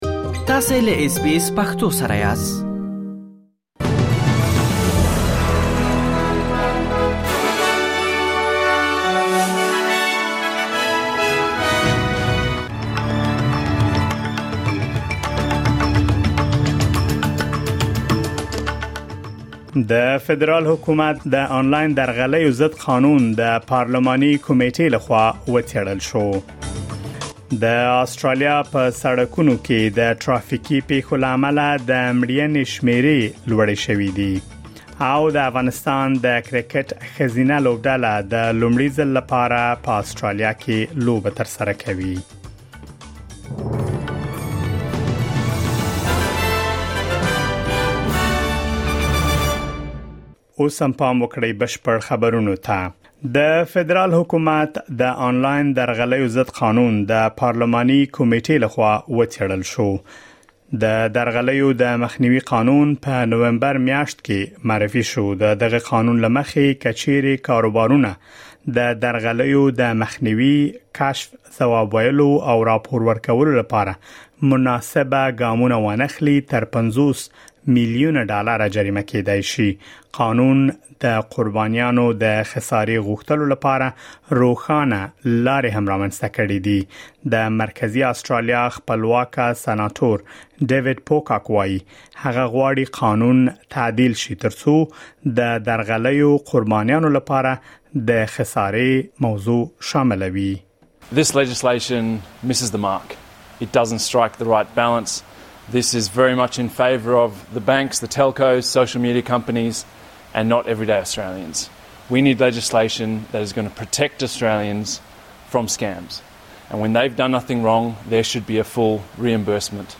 د آسټراليا او نړۍ مهم خبرونه